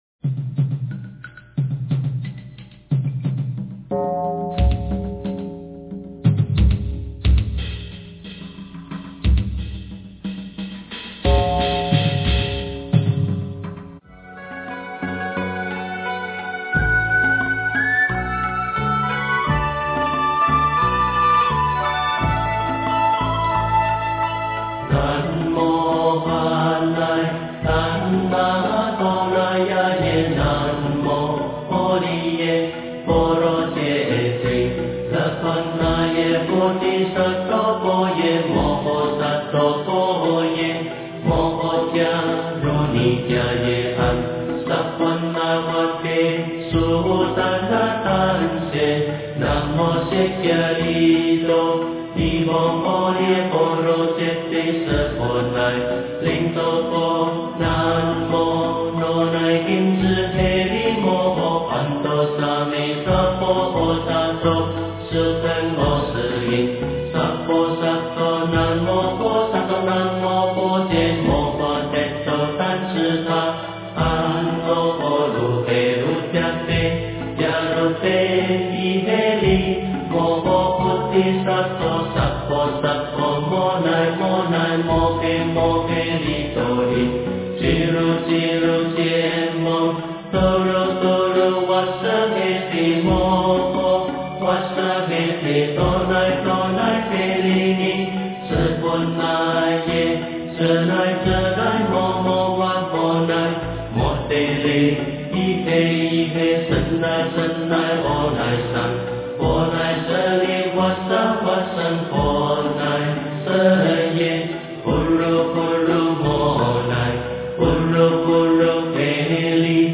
诵经
标签: 佛音诵经佛教音乐